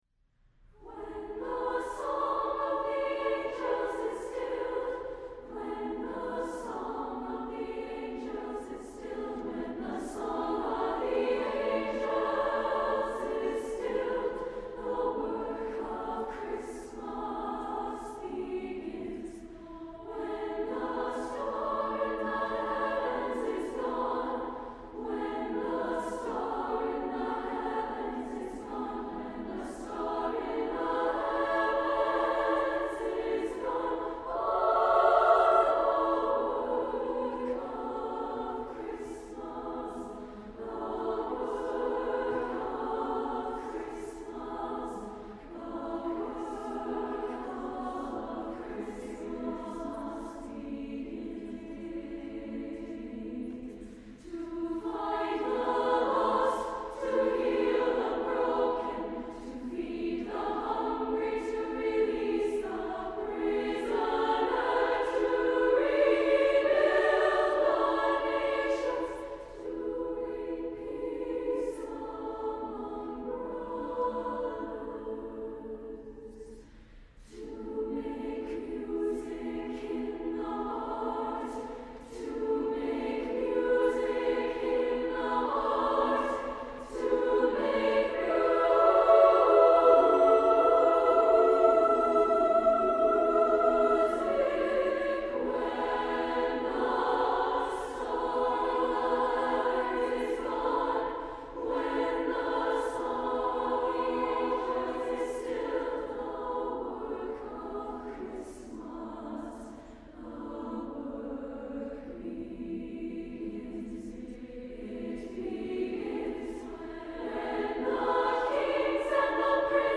A rhythmically vital setting
SSAA a cappella